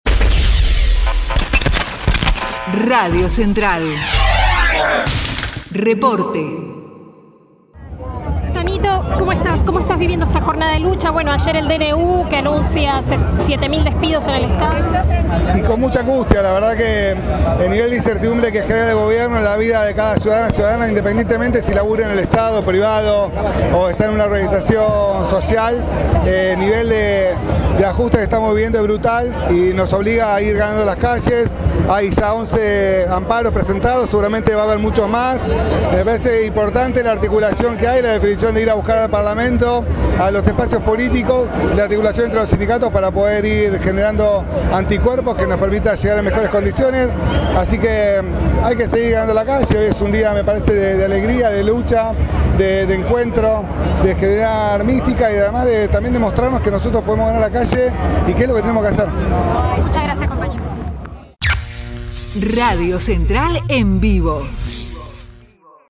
Contra el DNU de Milei - Marcha a Tribunales